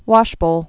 (wŏshbōl, wôsh-)